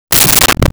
Wood Hit 04
Wood Hit 04.wav